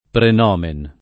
vai all'elenco alfabetico delle voci ingrandisci il carattere 100% rimpicciolisci il carattere stampa invia tramite posta elettronica codividi su Facebook praenomen [lat. pren 0 men ] s. m. (in it.); pl. praenomina [ pren 0 mina ]